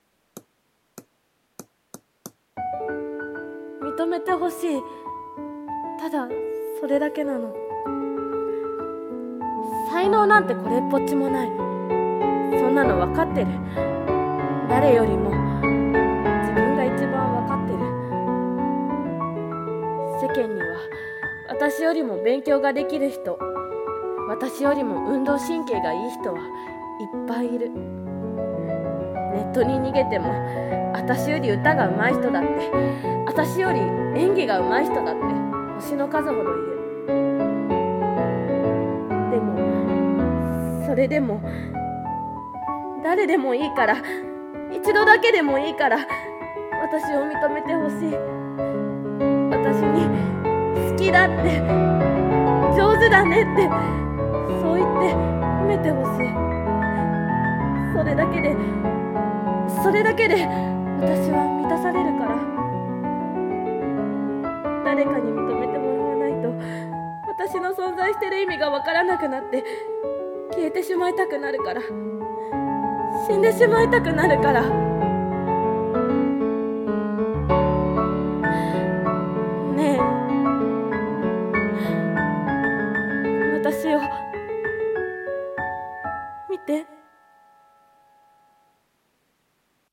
『承認欲求』【声劇台本】